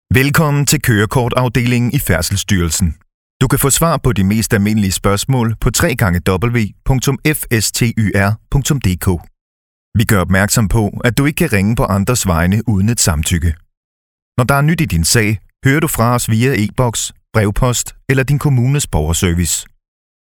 Mand
30-50 år
Reklame 2